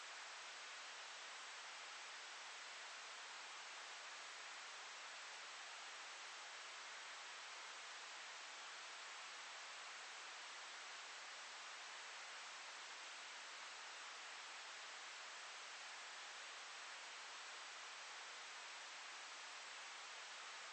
哈斯菲尔德警察聊天记录 " 白噪声调度
描述：发出白噪声